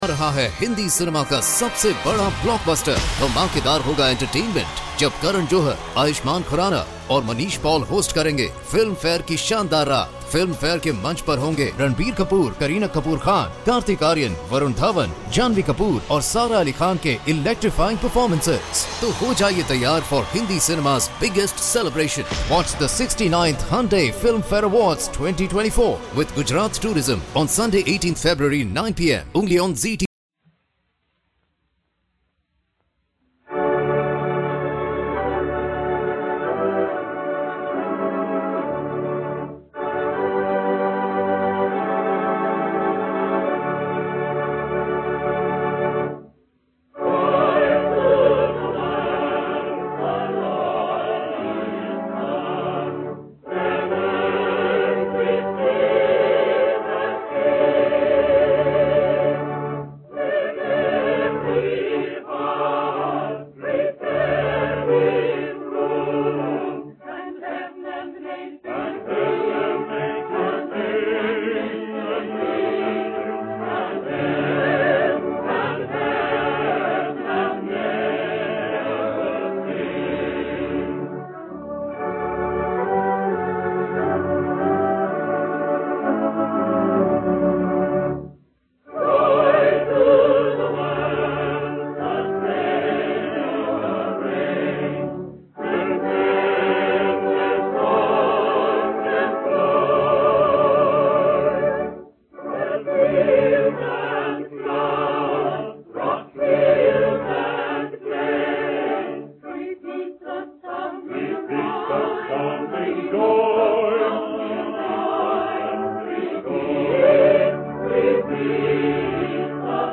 OTR Christmas Shows - Chorus and Organ - Joy To The World - 1943-11-xx 048 V-Disc A